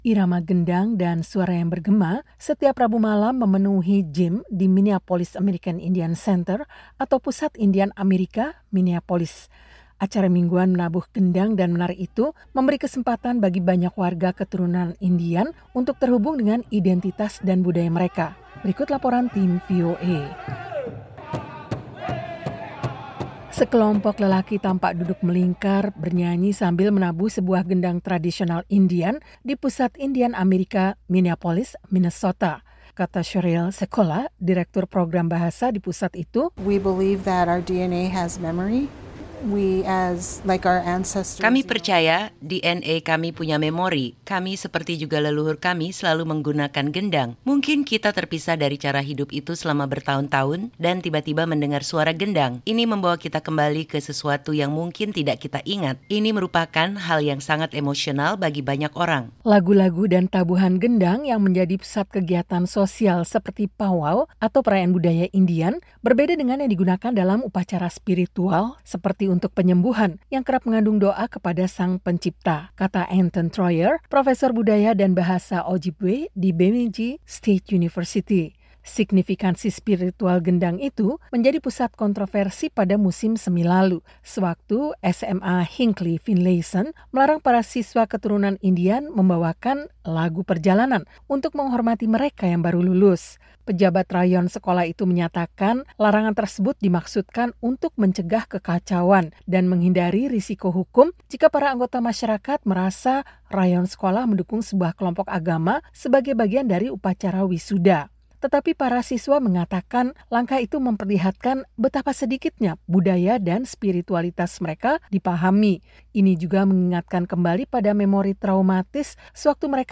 Irama gendang dan suara yang bergema memenuhi gymnasium di Minneapolis American Indian Center setiap Rabu malam. Acara mingguan menabuh gendang dan menari itu memberi kesempatan bagi banyak warga keturunan Indian untuk terhubung dengan identitas dan budaya mereka.